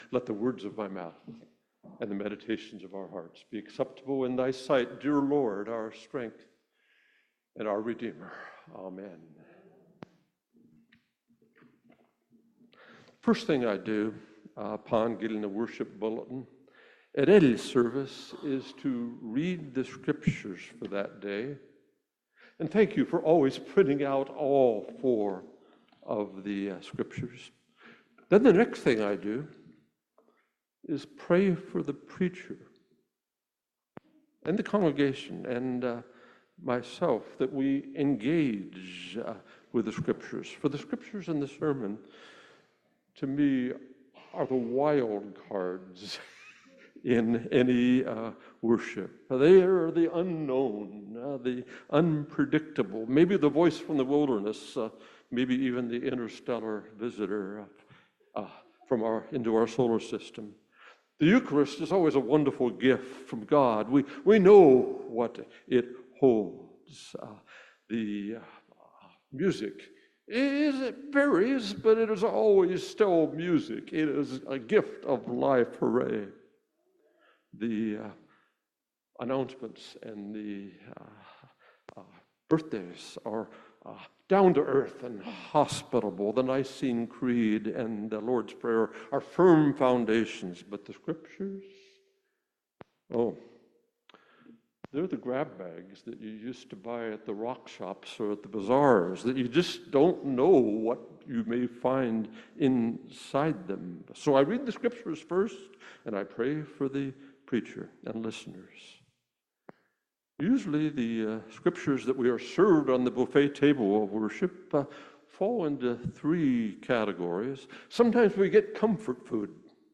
Leviathan in the book of Job is not a monster but something different, a force of nature created by God. In this sermon I plan to consider but brush aside three i